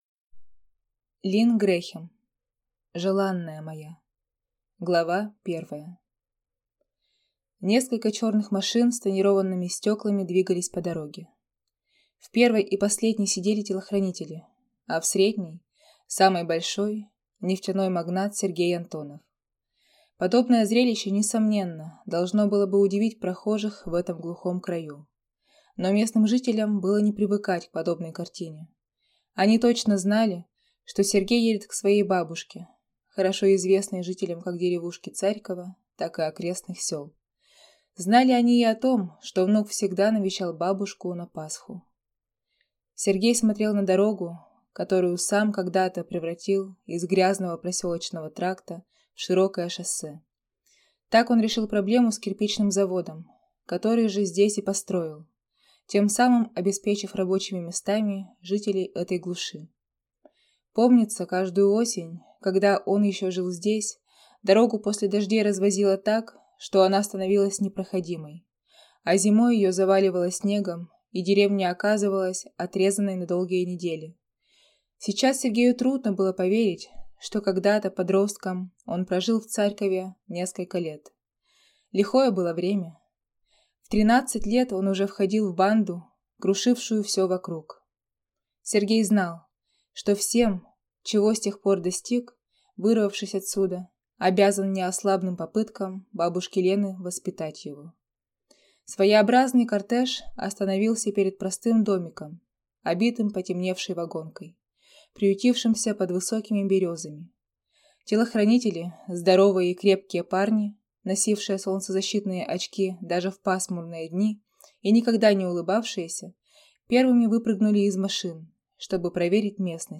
Аудиокнига Желанная моя | Библиотека аудиокниг